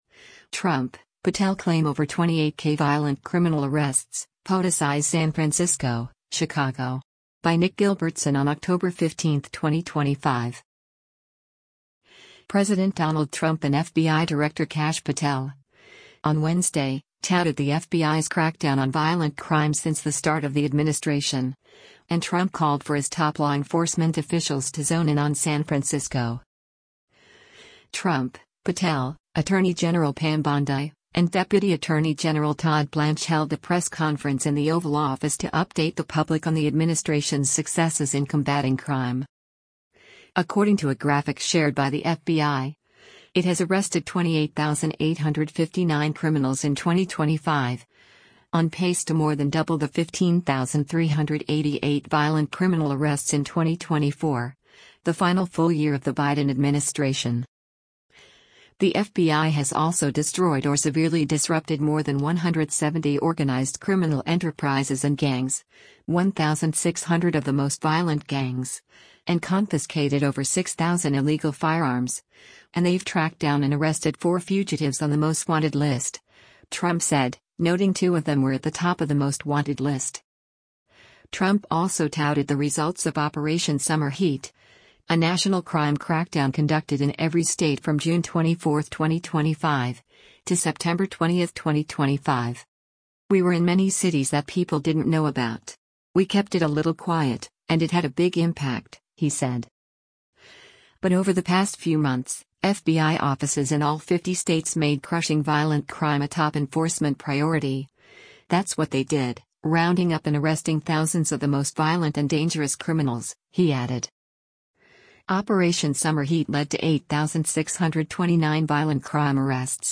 Trump, Patel, Attorney General Pam Bondi, and Deputy Attorney General Todd Blanche held a press conference in the Oval Office to update the public on the administration’s successes in combating crime.